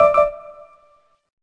Msntv 2 Error Sound Button - Free Download & Play
Sound Effects Soundboard243 views